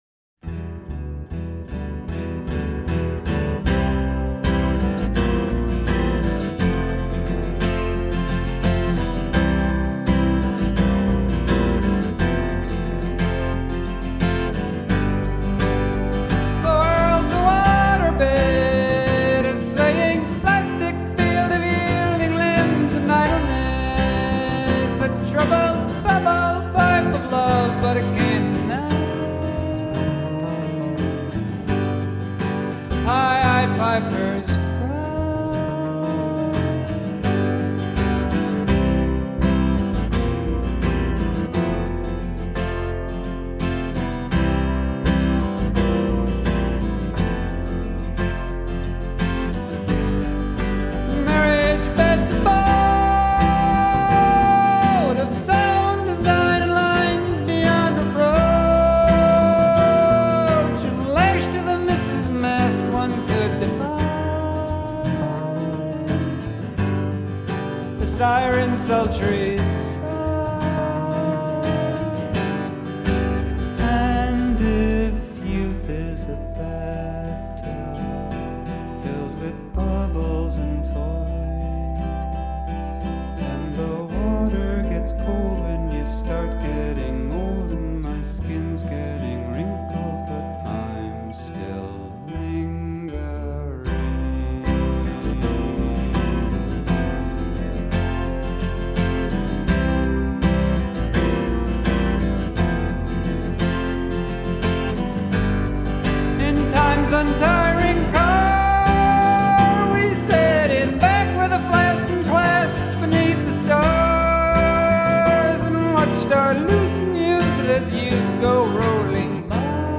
Imagine if Simon & Garfunkel had a sense of humor.